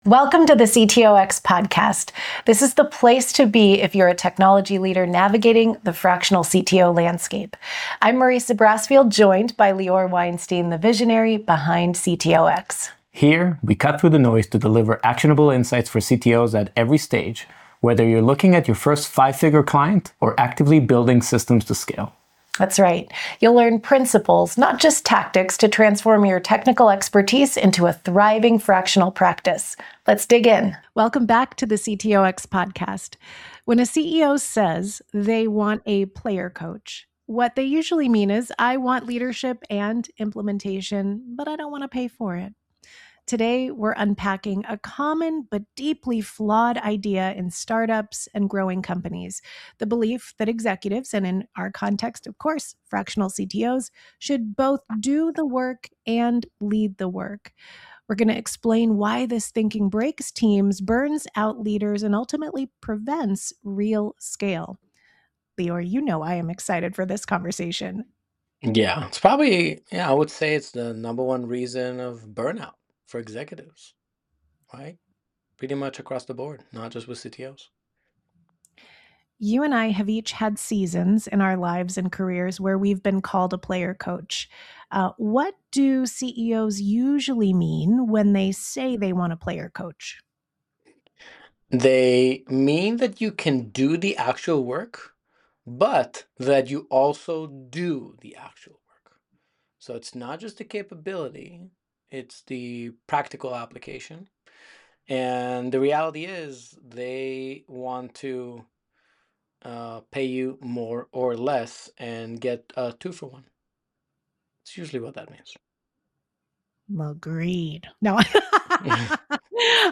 Podcast Description When a CEO says they want a player-coach, they usually mean they want two roles filled for the price of one. In this episode of the CTOX Podcast, hosts unpack why this expectation is not just flawed but genuinely dangerous to teams, leaders, and company growth.